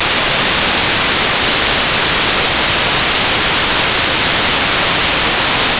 つまり、22.05 / 4 = 5.5125kHz 以上の周波数をカットします。
だいたい、-40dB減衰してます。
(音がデカイので注意)
出力信号